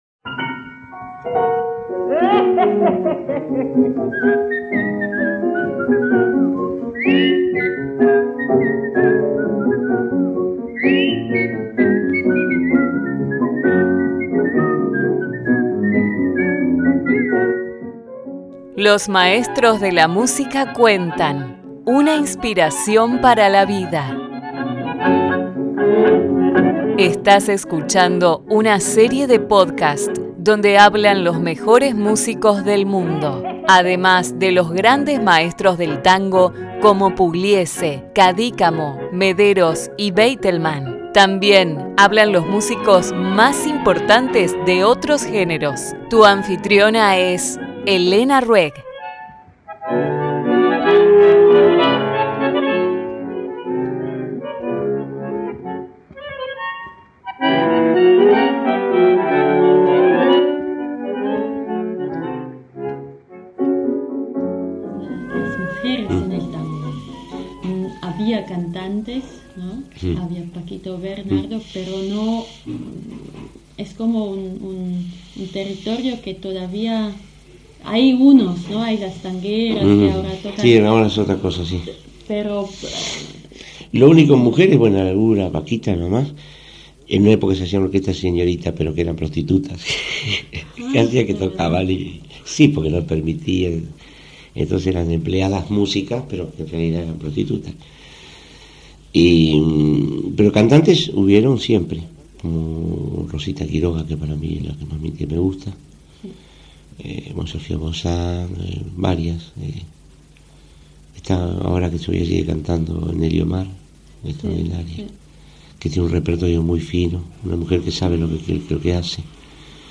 Today you will listen to the interview with the emblematic poet, composer, singer and guitarist Juan Cedrón,